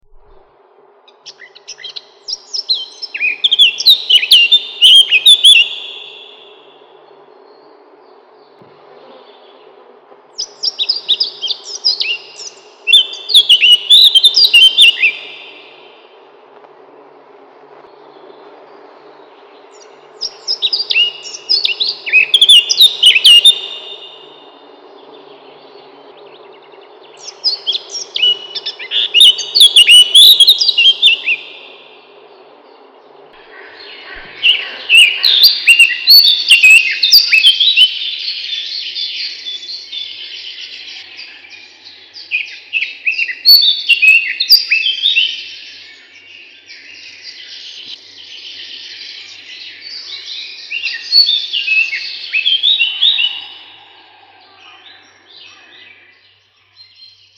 На этой странице собраны разнообразные звуки славки – от коротких позывов до продолжительных трелей.
Звуки славки мельника